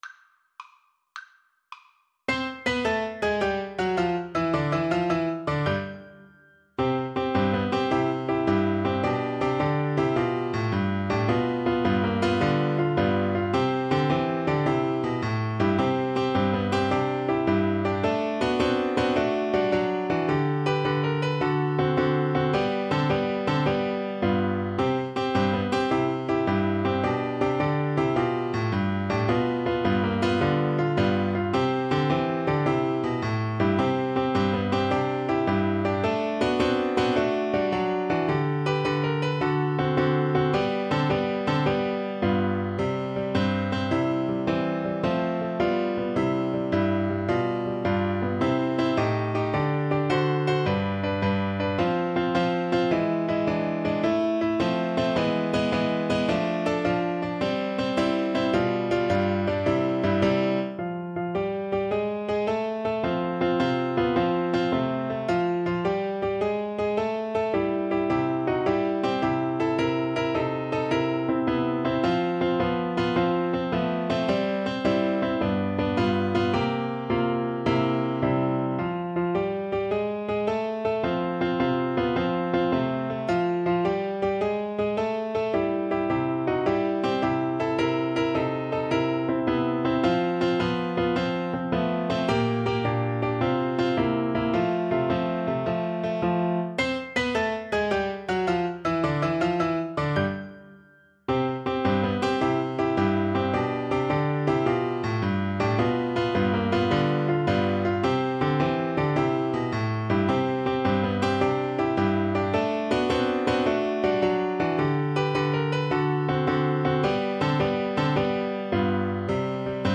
Classical Sousa, John Philip Liberty Bell March Flute version
FlutePiano
6/8 (View more 6/8 Music)
Allegro con brio = 160 (View more music marked Allegro)
C major (Sounding Pitch) (View more C major Music for Flute )
Classical (View more Classical Flute Music)
Marching Music for Flute s